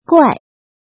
怎么读
guài
guai4.mp3